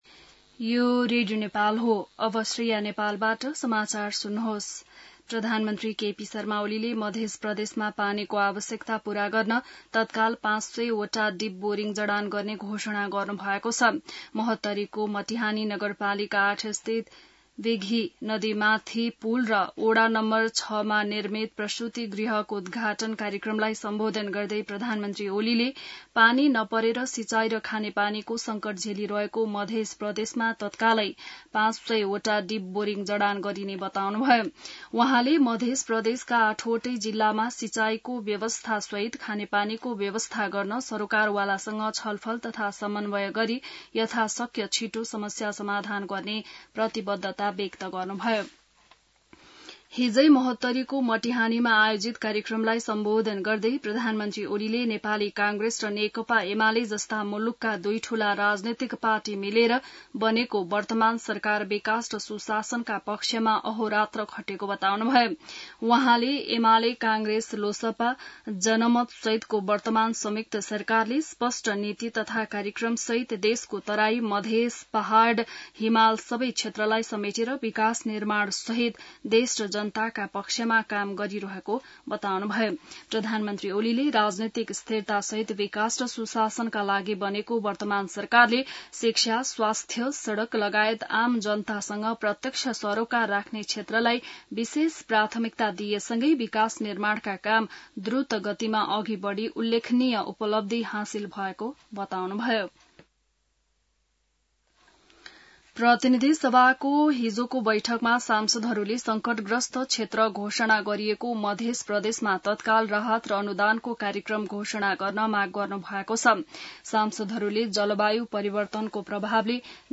बिहान ६ बजेको नेपाली समाचार : १० साउन , २०८२